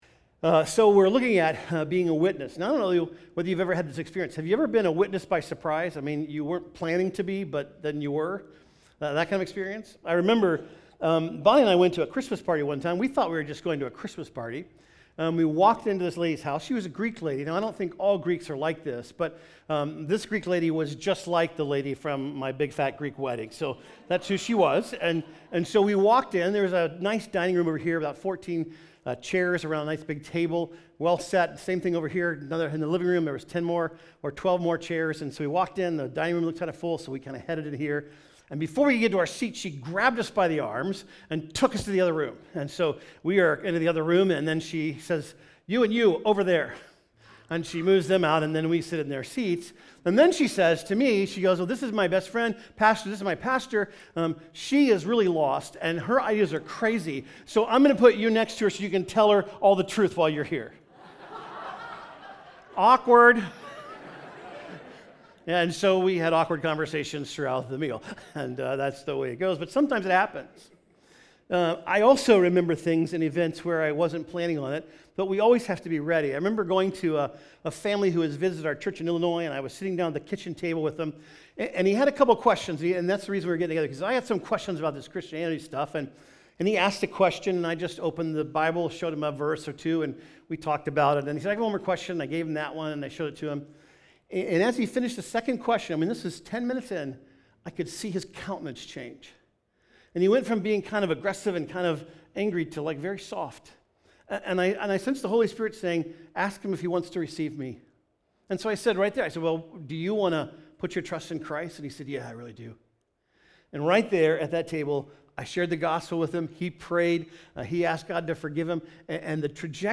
In this message we look at the difference between your part and God's part.